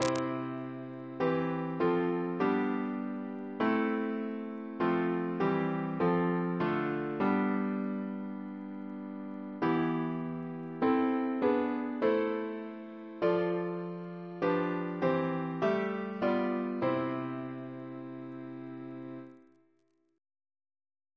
Double chant in C Composer: Henry G. Ley (1887-1962) Reference psalters: ACB: 271